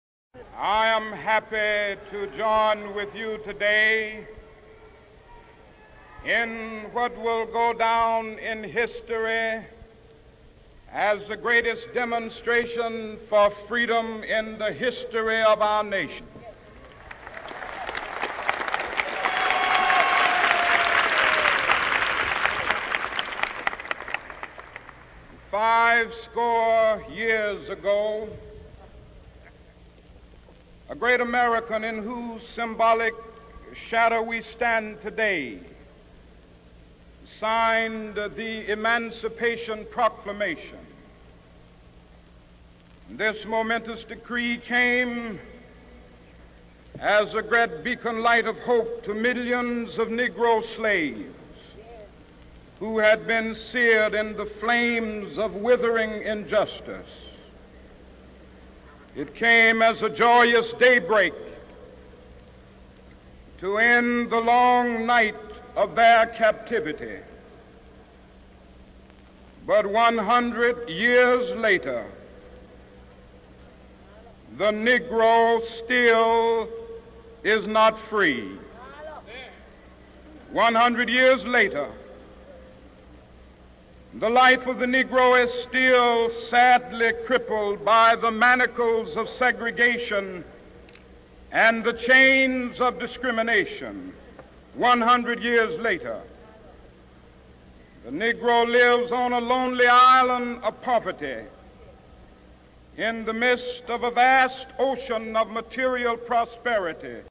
名人励志英语演讲 第58期:我有一个梦想(1) 听力文件下载—在线英语听力室